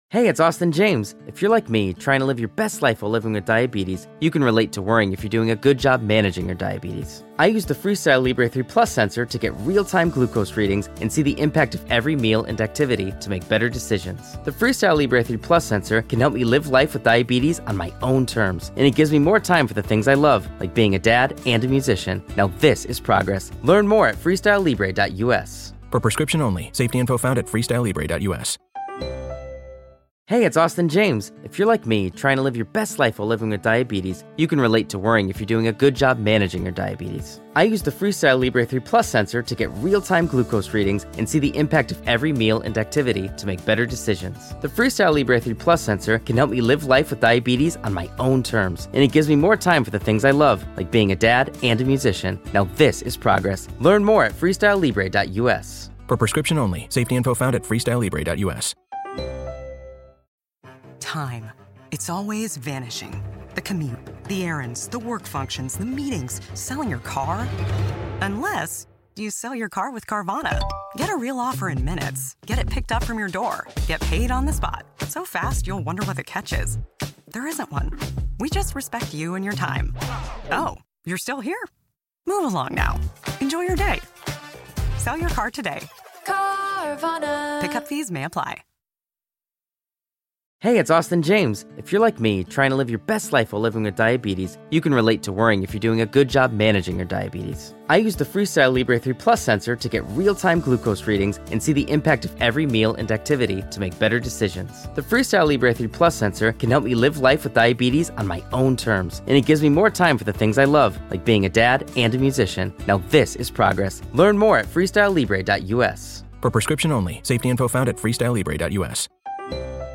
a horror movie review podcast
talks with comedians, actors, and filmmakers about horror movies!
The Cave Podcast Studio